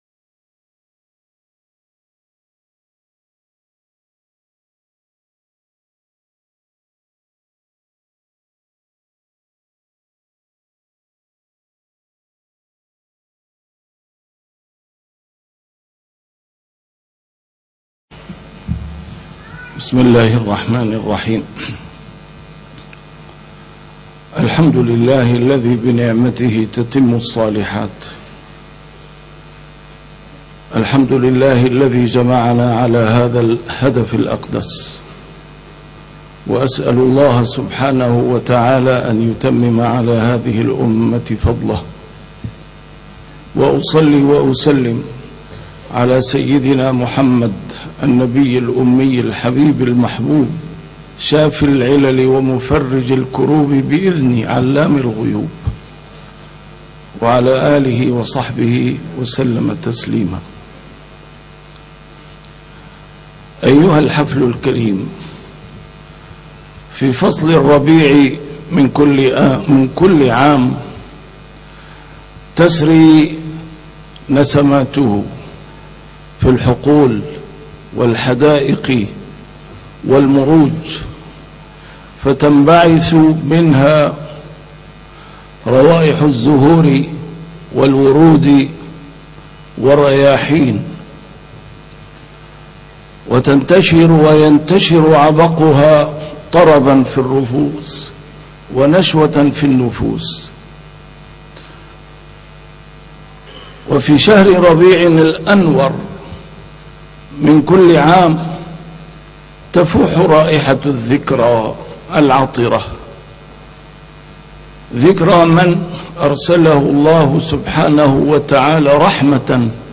A MARTYR SCHOLAR: IMAM MUHAMMAD SAEED RAMADAN AL-BOUTI - الدروس العلمية - محاضرات متفرقة في مناسبات مختلفة - كلمة العلامة البوطي في حفل جائزة البردة